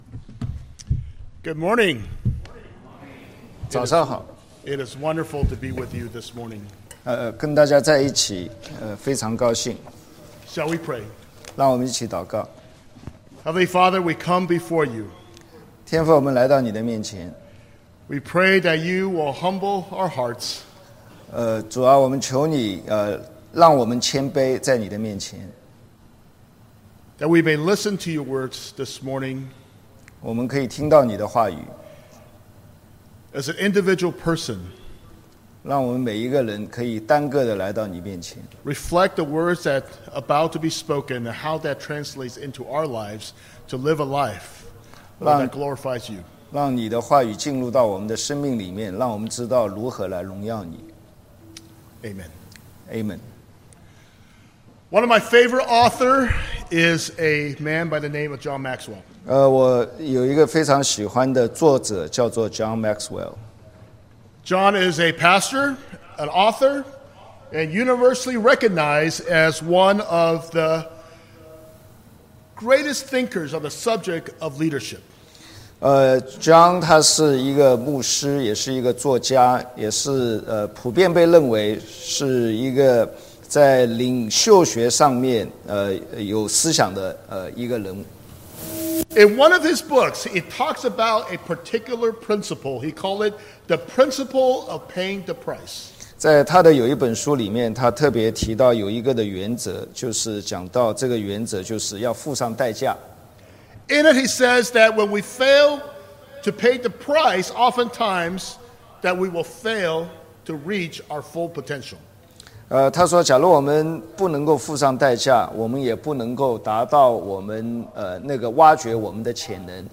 Passage: 1 Corinthians 15: 1-10 Service Type: Sunday Worship